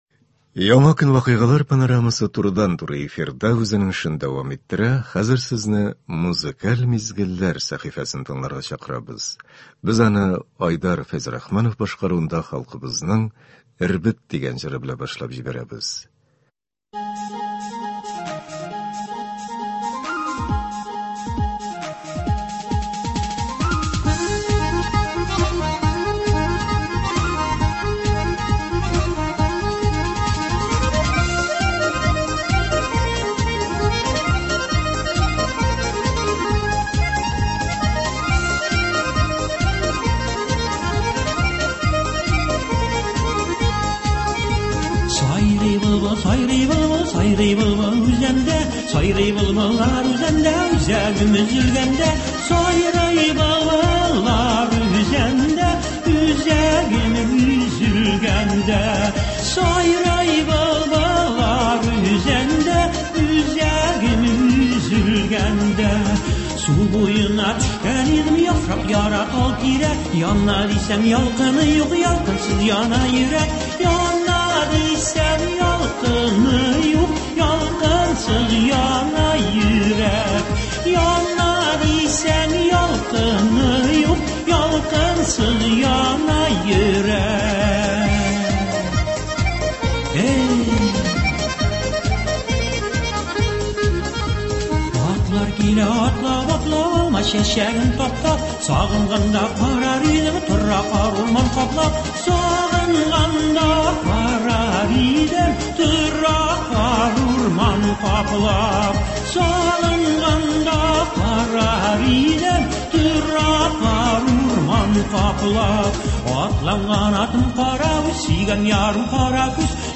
Иртәләрне моңлы җырлар белән башлыйбыз